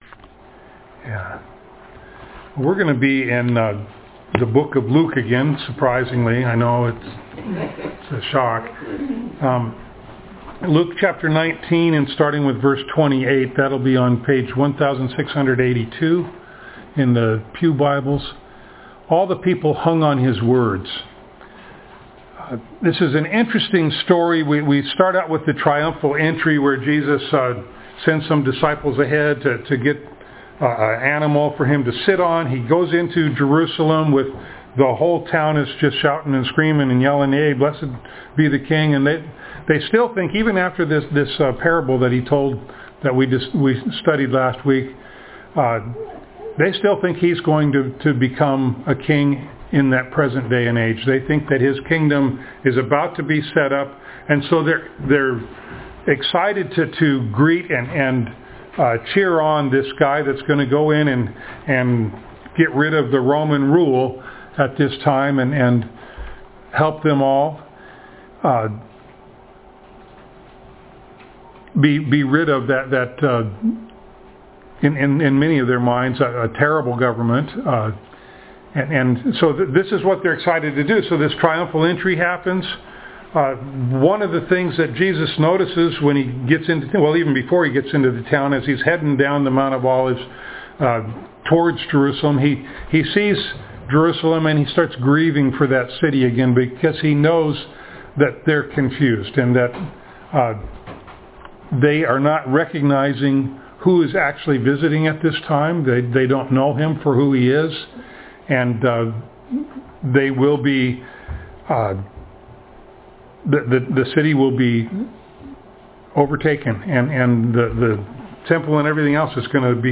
Ephesians 4:26-27,31-32 Service Type: Sunday Morning Download Files Notes « The “Well Done” Reward